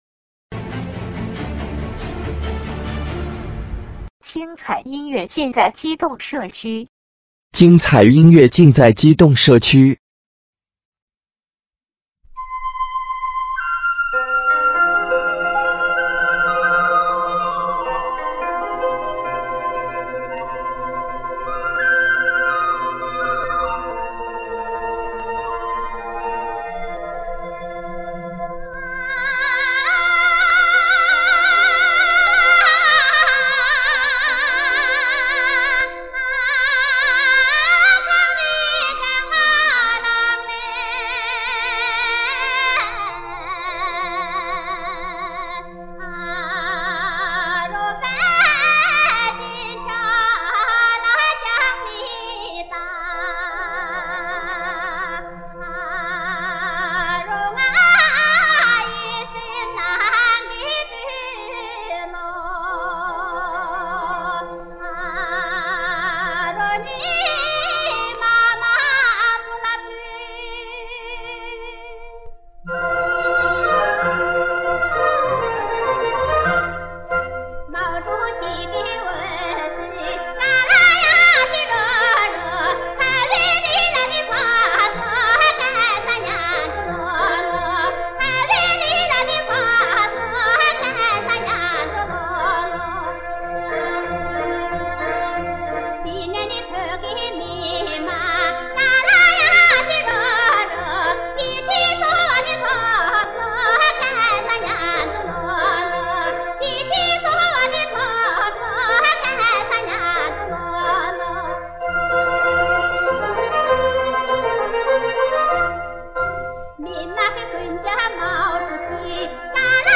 藏语演唱